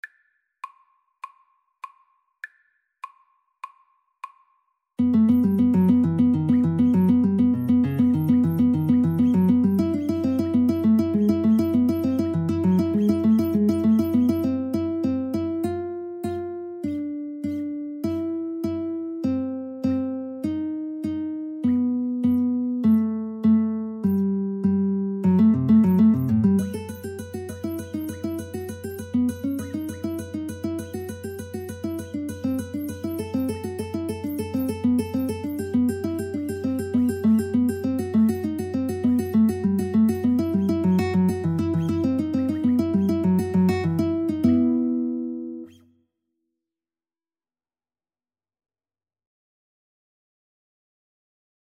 Classical (View more Classical Guitar Duet Music)